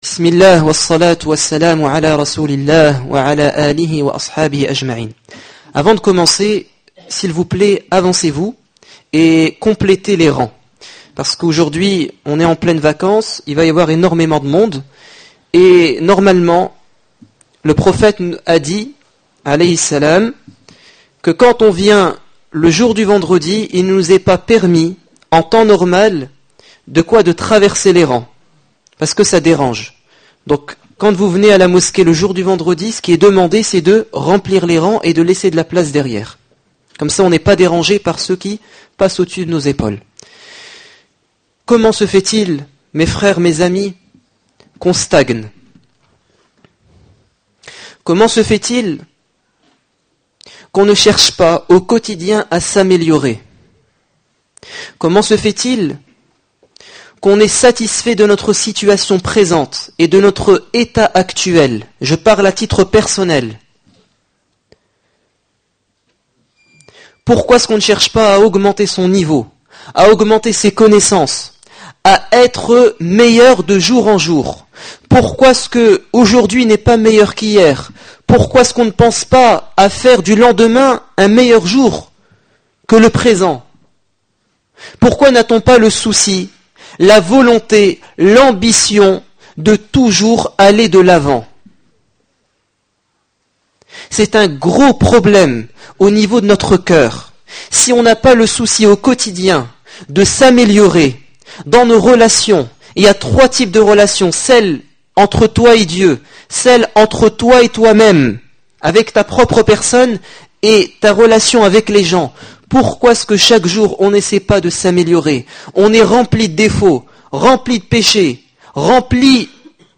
Discours du 26 avril 2013
Accueil Discours du vendredi Discours du 26 avril 2013 Comment s'améliorer de jour en jour ?